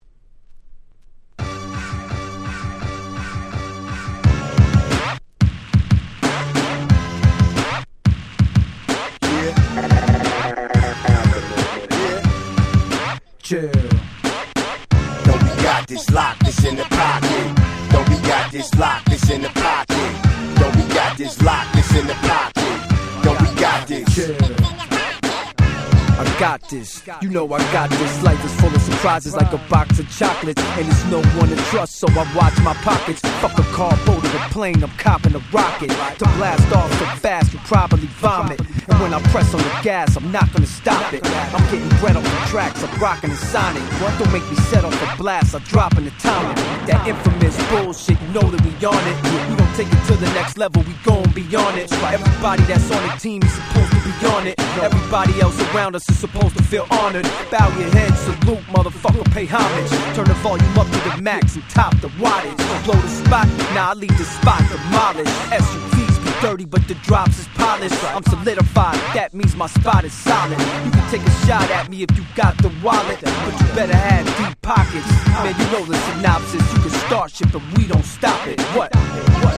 05' Nice Boom Bap / Hip Hop !!
ブーンバップ